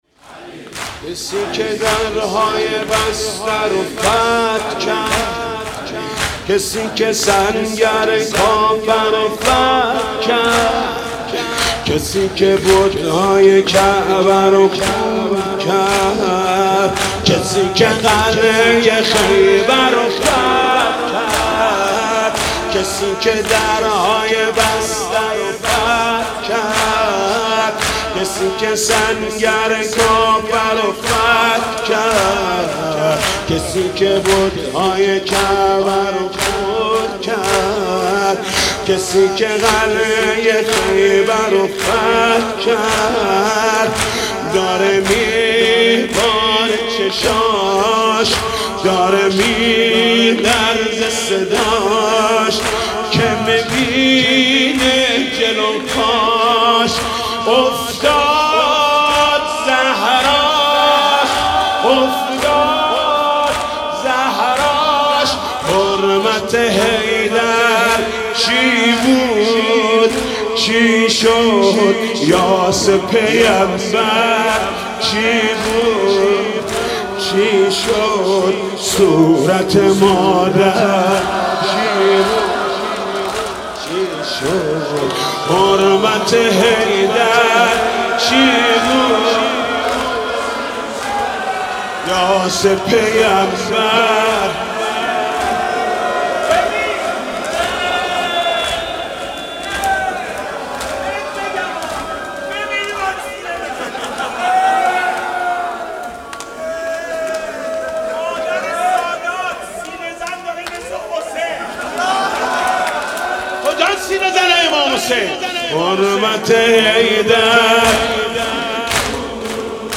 «فاطمیه 1396» زمینه: حرمت حیدر چی بود! چی شد